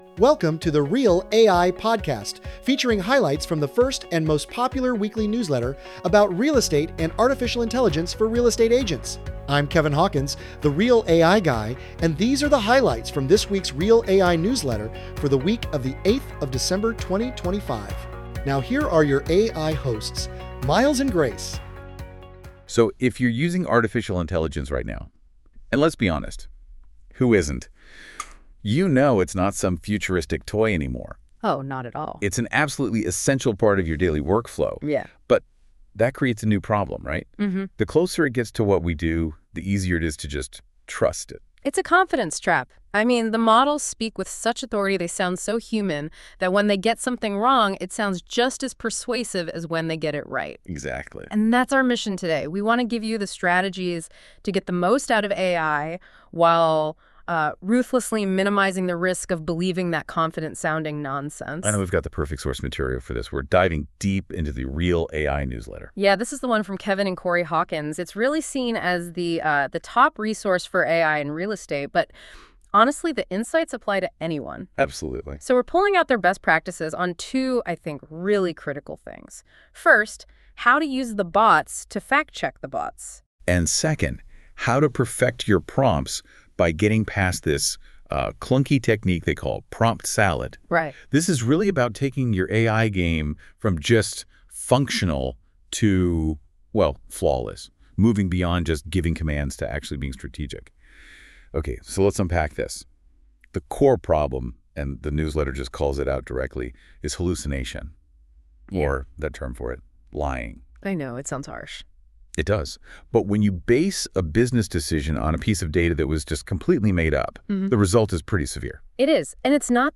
Check out our new The REAL AI Podcast for this week – Episode 4 12/8/25 – AI generated with host Miles and Grace!